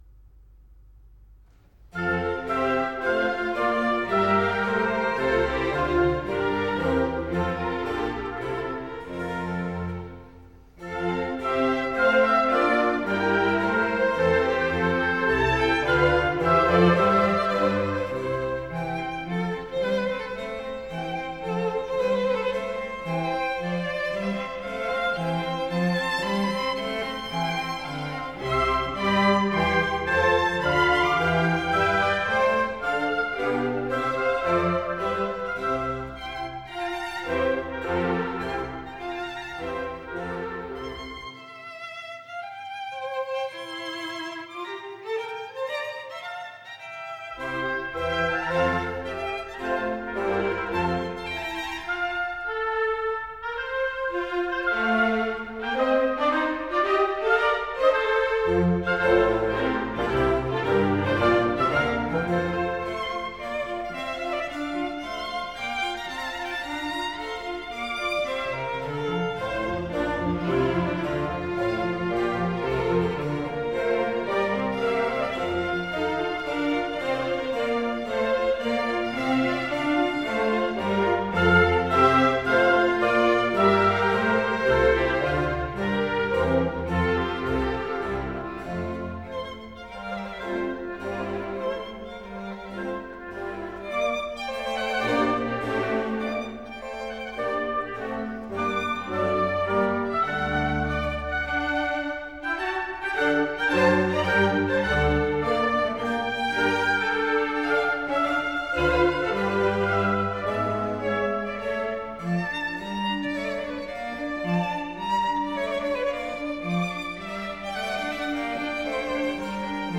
Andante larghetto